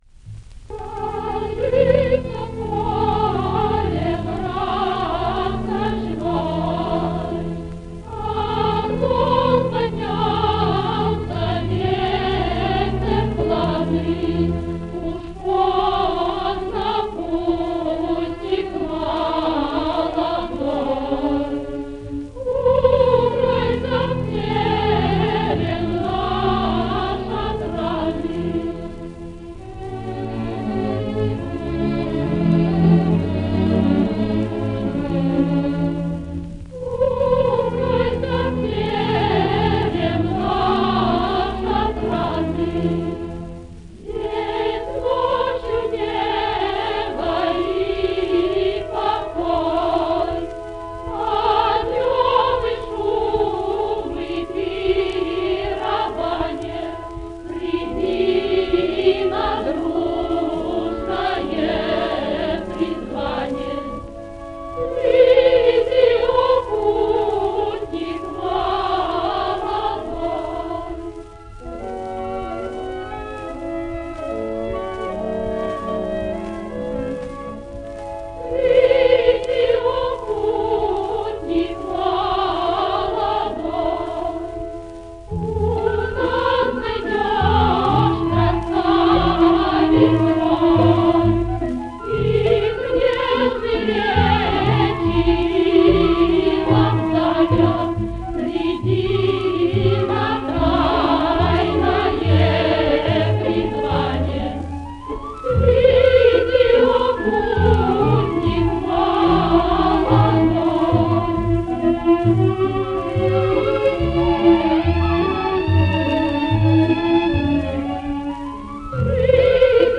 меццо-сопрано
Персидский хор из оперы «Руслан и Людмила». Хор и оркестр Большого театра. Дирижёр С. А. Самосуд.